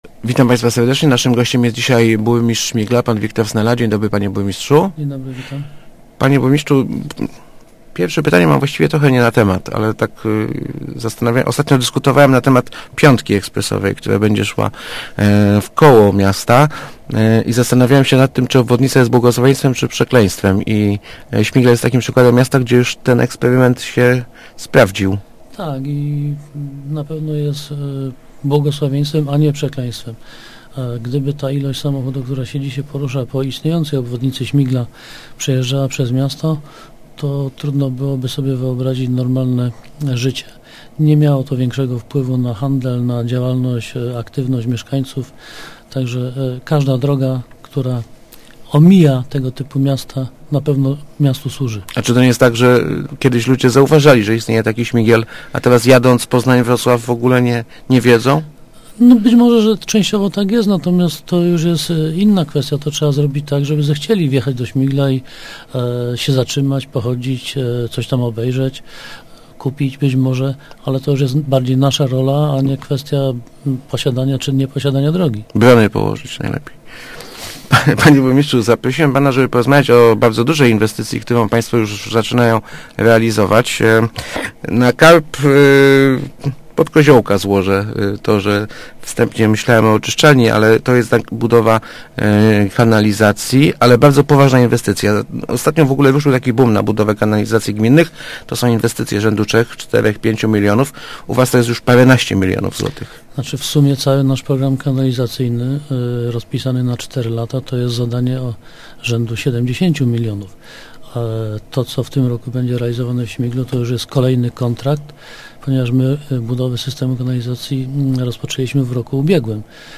Będzie ona kosztować ponad 13 milionów złotych. Ta część gminnej kanalizacji będzie gotowa do listopada - mówił w Rozmowach Elki burmistrz Wiktor Snela.